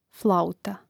flȁuta flauta